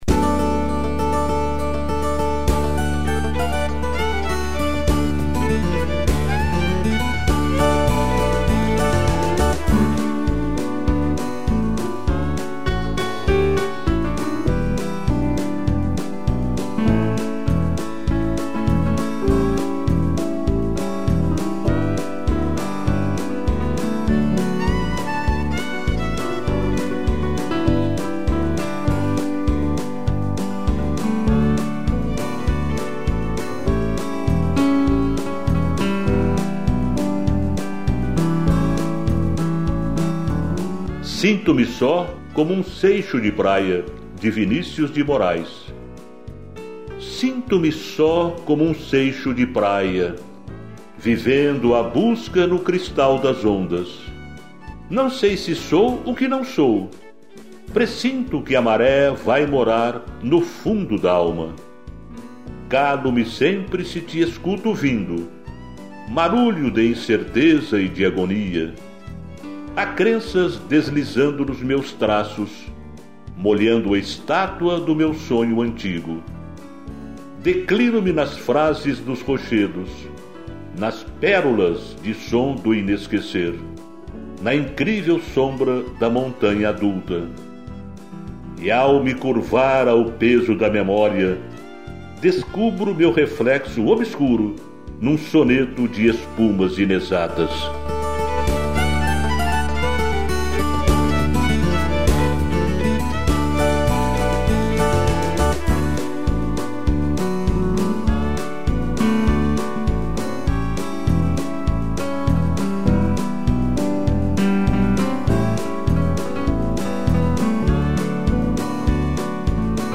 violino